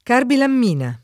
vai all'elenco alfabetico delle voci ingrandisci il carattere 100% rimpicciolisci il carattere stampa invia tramite posta elettronica codividi su Facebook carbilammina [ karbilamm & na ] o carbilamina [ karbilam & na ] s. f. (chim.)